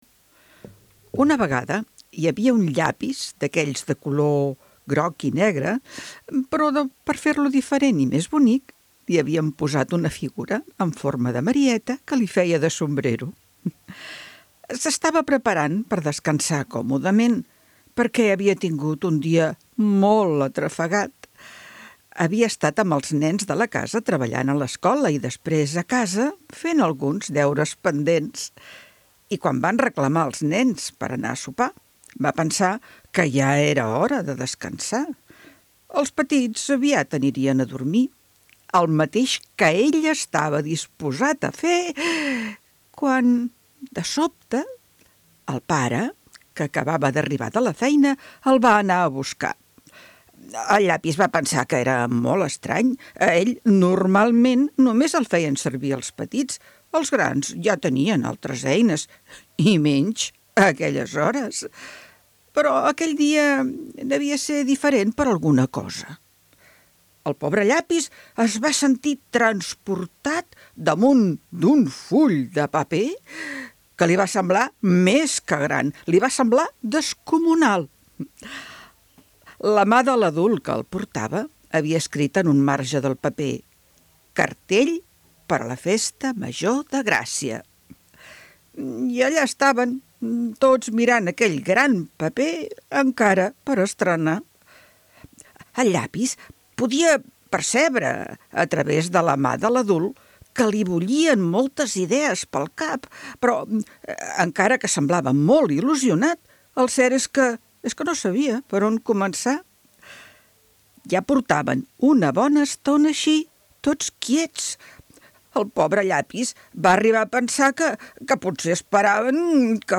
CONTA CONTES PER A INFANTS